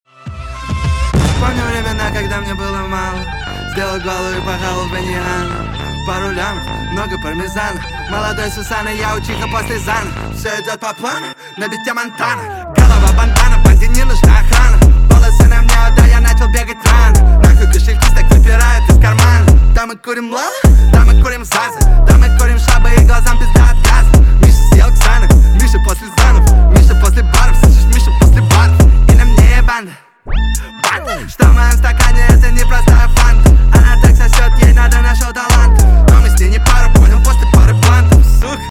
• Качество: 192, Stereo
мужской голос
русский рэп
Rap